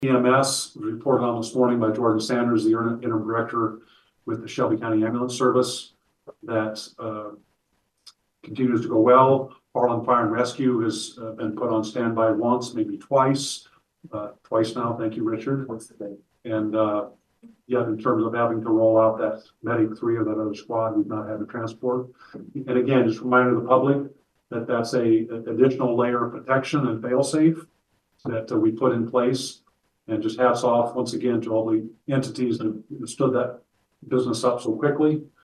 (Harlan) Harlan City Administrator Gene Gettys updated the City Council on Tuesday on the countywide ambulance service.